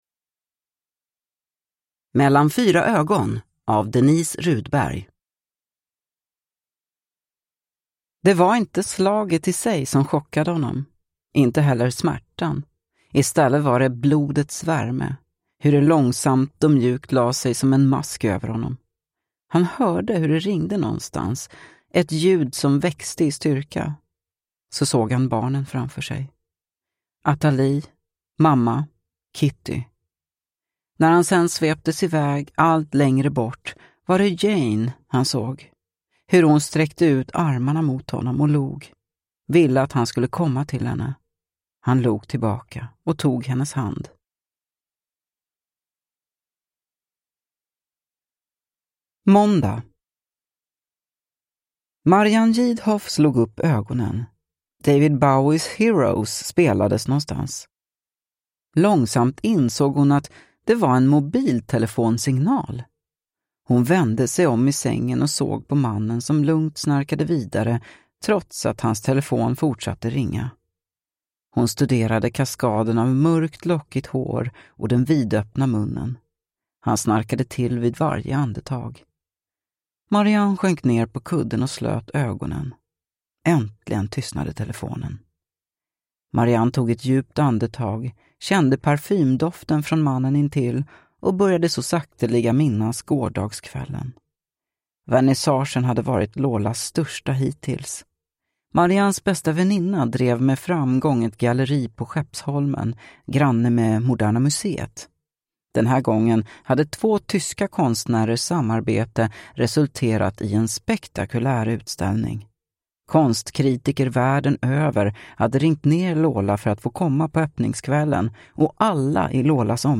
Mellan fyra ögon – Ljudbok